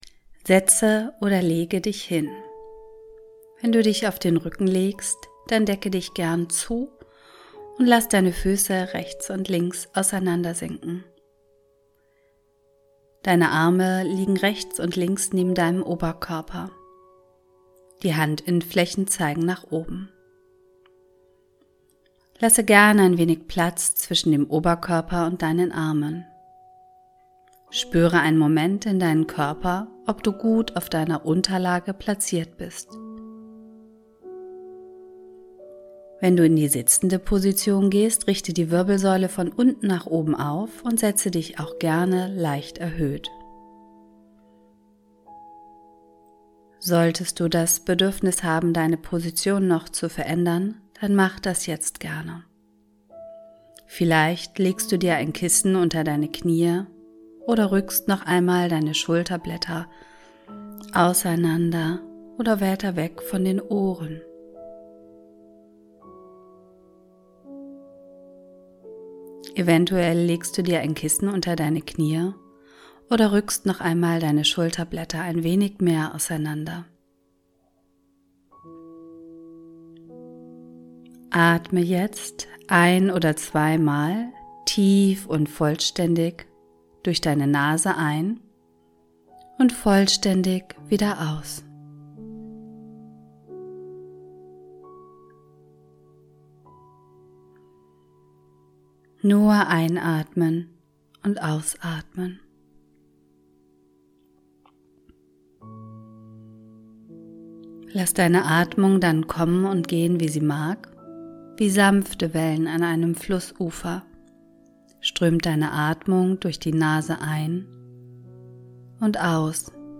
Du hörst im Mindful Minds Podcast kurze Meditationen, einfache Yoga Sequenzen, spannende Interviews.
Ich begleite dich durch Atemübungen und Meditationen, Entspannungsübungen und Achtsamkeit im Leben.